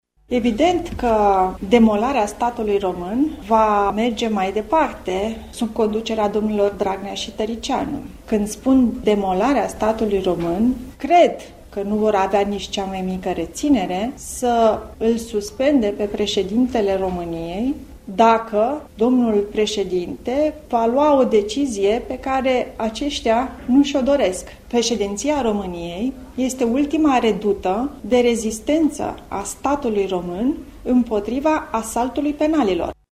Într-o conferinţă de presă susţinută, astăzi, la Sibiu, prim-vicepreşedintele PNL, Raluca Turcan, a acuzat coaliţia PSD-ALDE că face un asalt împotriva justiţiei, democraţiei şi statului de drept: